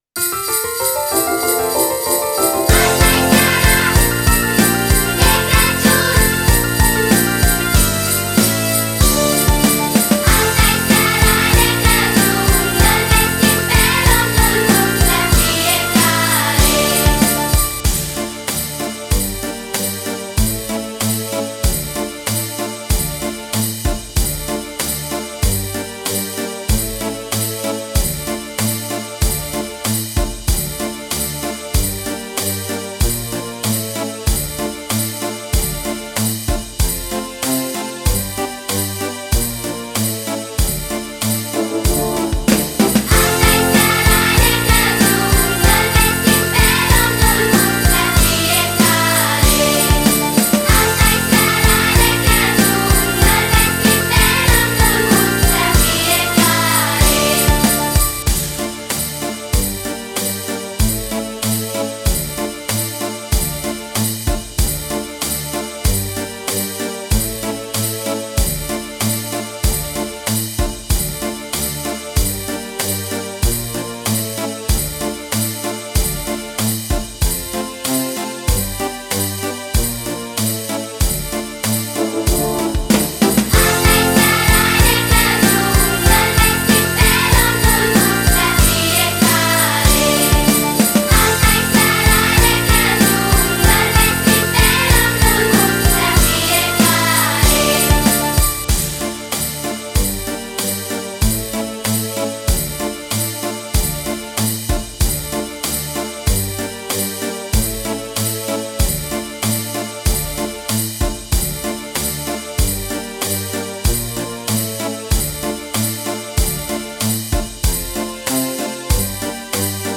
Free Christmas Carols Backing Tracks